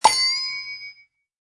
mailbox_alert.mp3